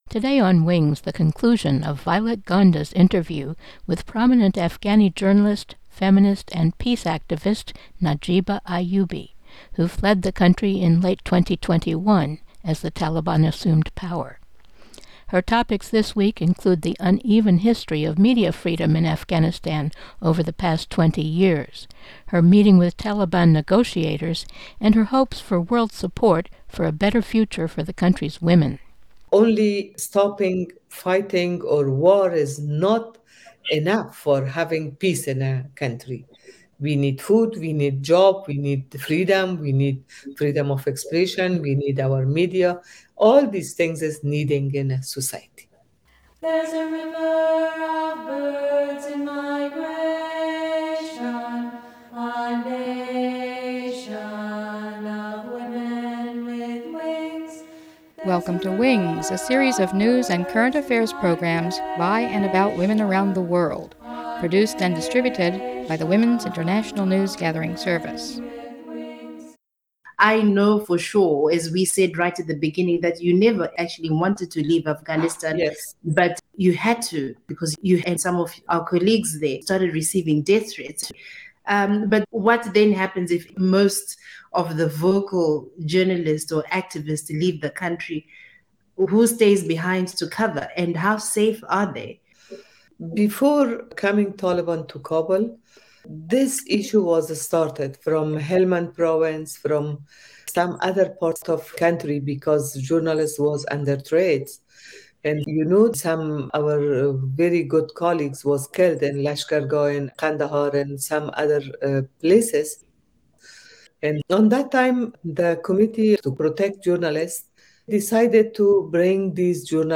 interviews journalist